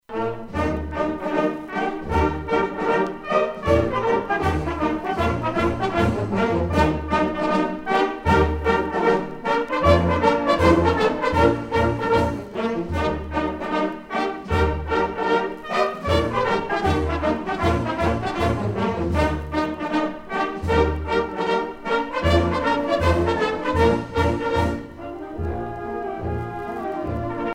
Fonction d'après l'analyste gestuel : à marcher
Catégorie Pièce musicale éditée